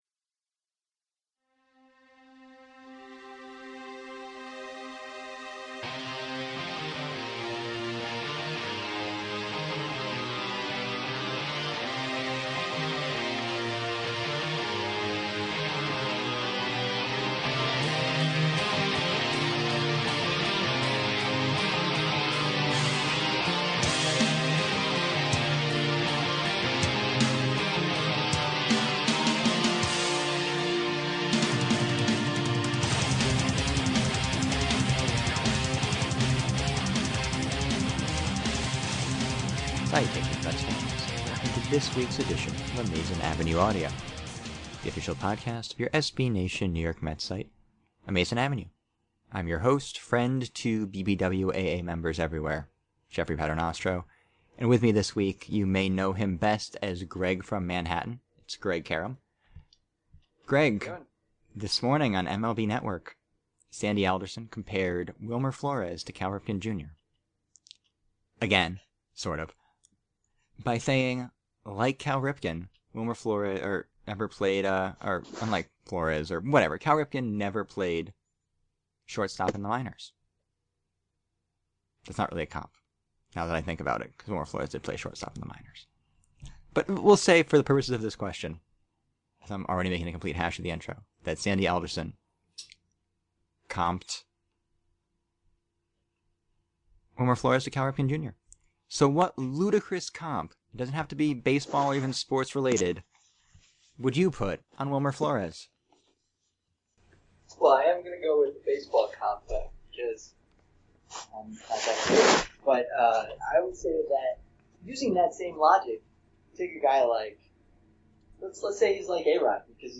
Yes, that's just a fancy way of saying it is an e-mail show. We talk about where we would play in a hypothetical Amazin' Avenue charity baseball game, debate the merits of the "Warthen slider," and issue a plea for more neutral minor league parks.